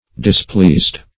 Displeased - definition of Displeased - synonyms, pronunciation, spelling from Free Dictionary
displeased.mp3